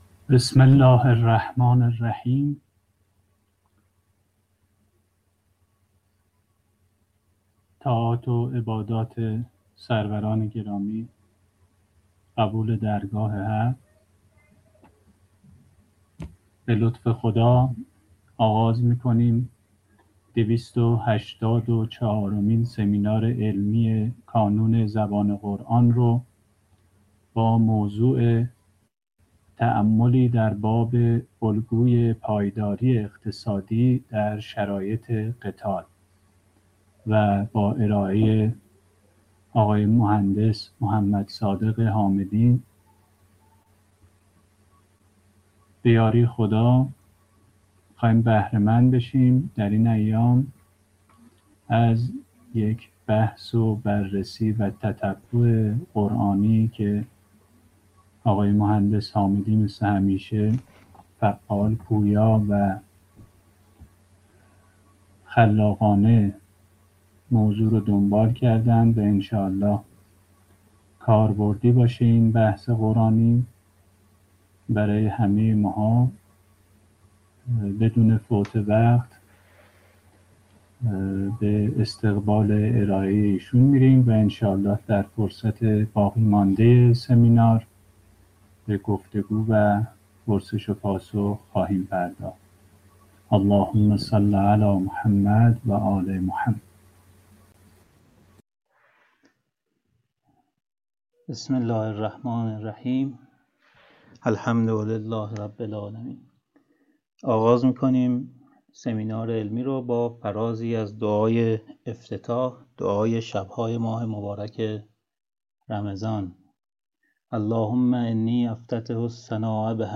تأملی در باب الگوی پایداری اقتصادی در شرایط قتال : 284 سمینار علمی